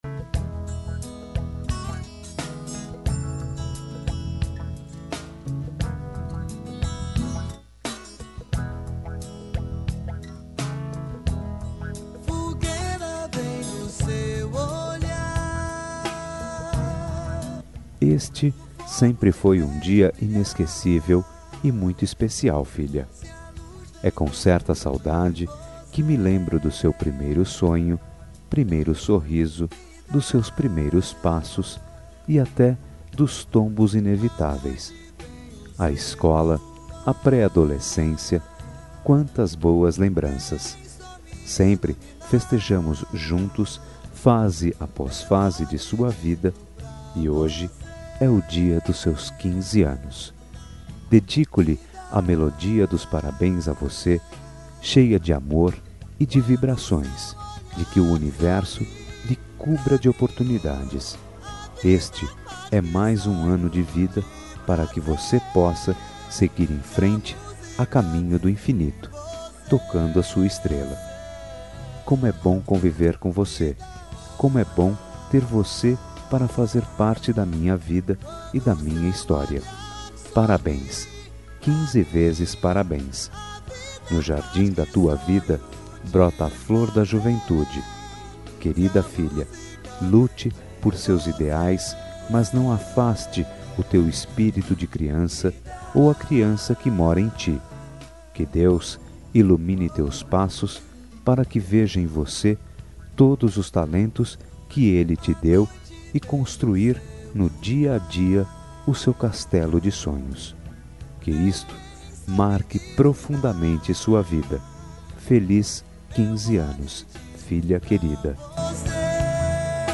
Aniversário de 15 Anos – Voz Masculina – Cód: 33382 – Pais enviando